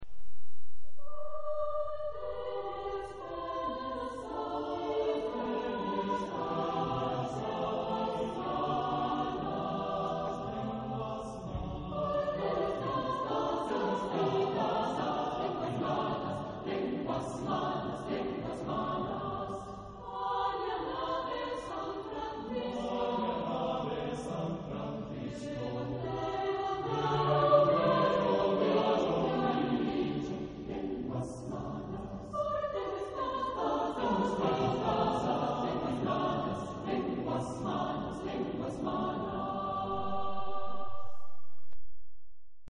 Genre-Stil-Form: Potpourri ; Renaissance ; weltlich
Charakter des Stückes: geschmeidig ; dynamisch ; schlüpfrig
Chorgattung: SATB  (4 gemischter Chor Stimmen )
Tonart(en): A äolisch